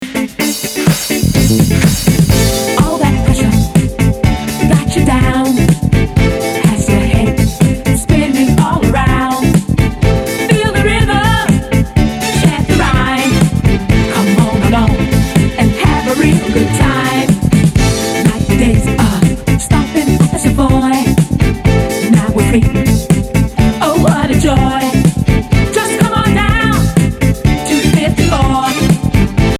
Spaß, Power und das Feeling der wilden 80er Jahre.
Diese CD heizt richtig ein!